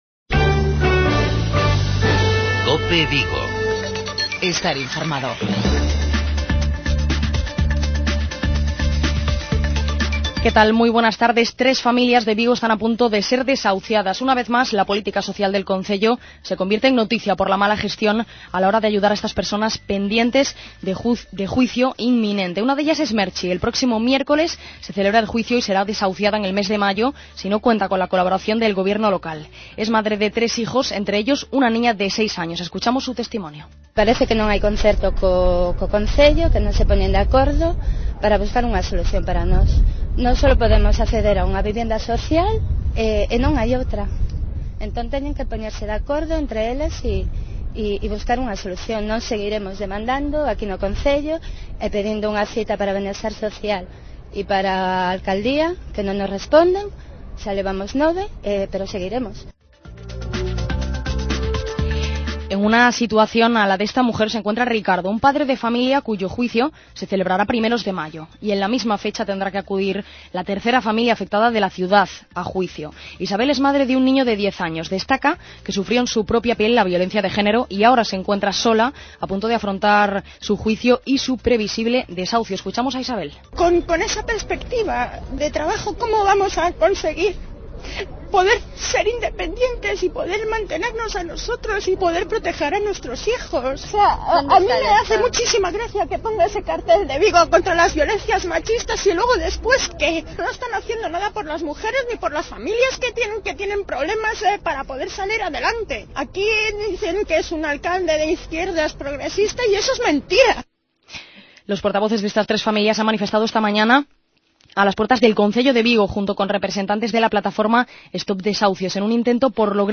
Informativos Vigo